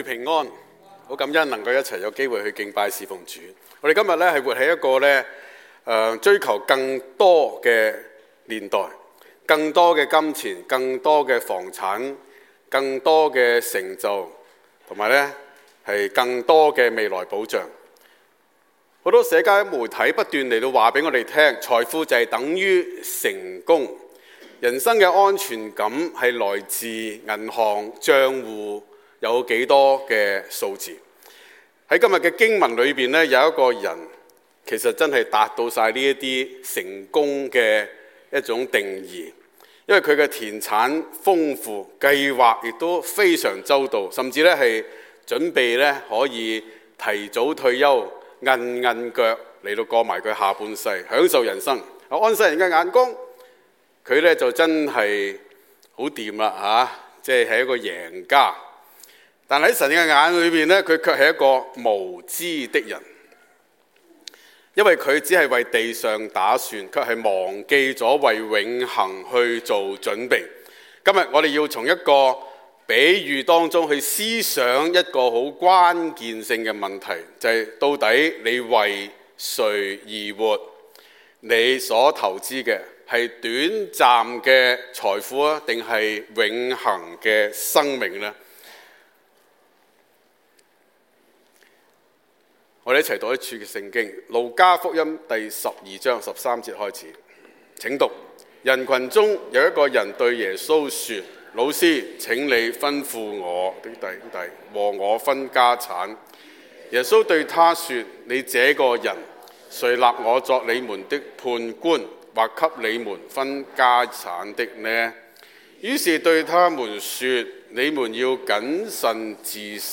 在： Sermon